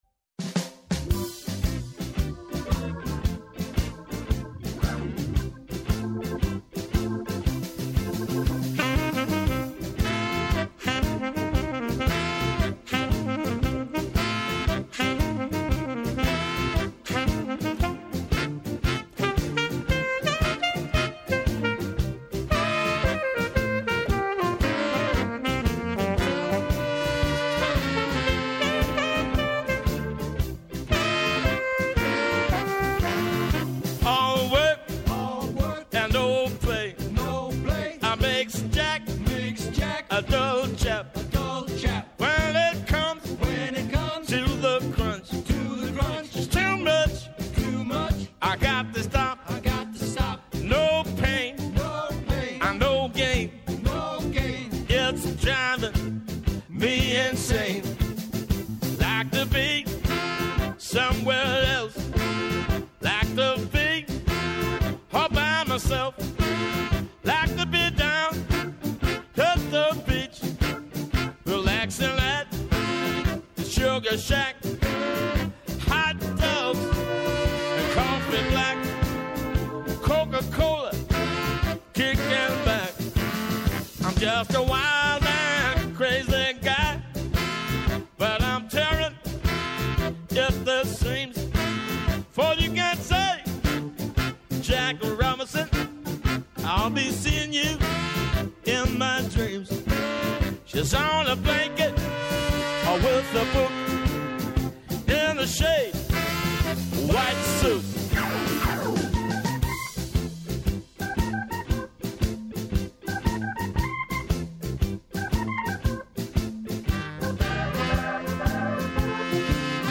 Άνθρωποι της επιστήμης, της ακαδημαϊκής κοινότητας, πολιτικοί, ευρωβουλευτές, εκπρόσωποι Μη Κυβερνητικών Οργανώσεων και της Κοινωνίας των Πολιτών, συζητούν για όλα τα τρέχοντα και διηνεκή ζητήματα που απασχολούν τη ζωή όλων μας, από την Ελλάδα και την Ευρώπη μέχρι την άκρη του κόσμου.